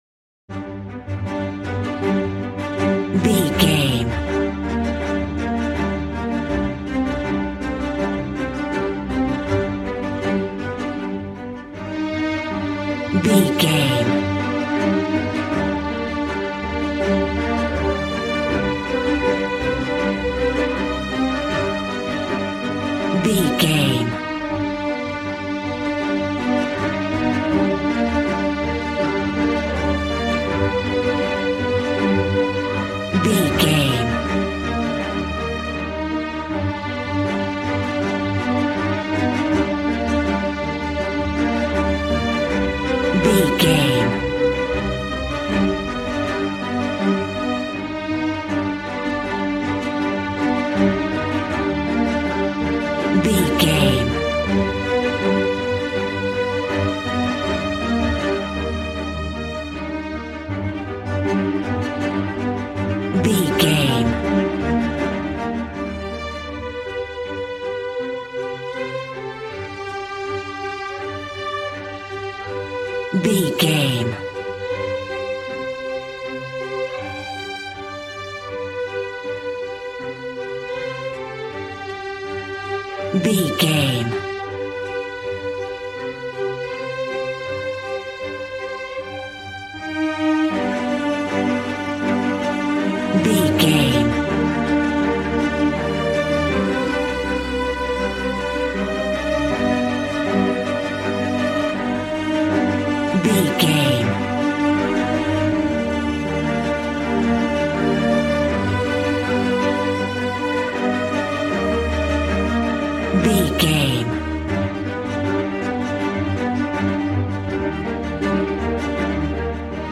Regal and romantic, a classy piece of classical music.
Ionian/Major
Fast
regal
strings
brass